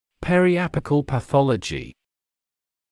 [ˌperɪ’æpɪkl pə’θɔləʤɪ] [ˌпэри’эпикл пэ’солэджи] периапикальная патология